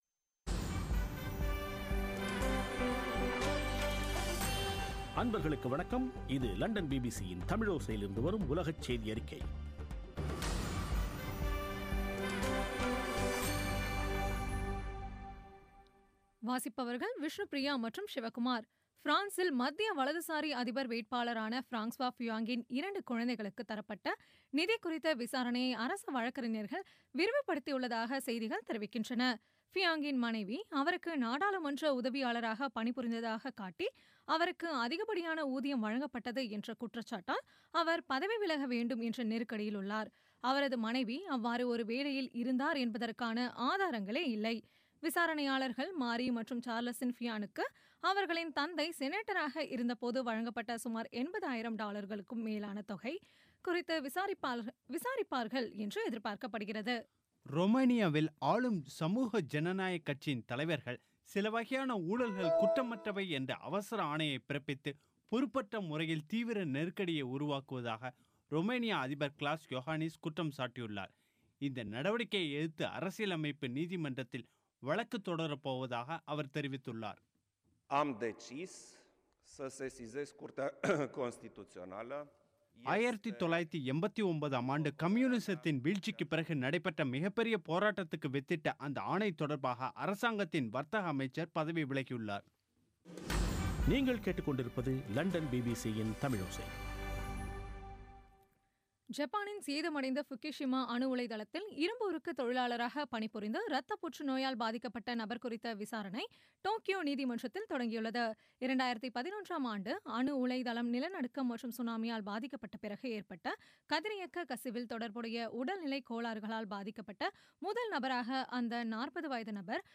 பிபிசி தமிழோசை செய்தியறிக்கை (02.02.17)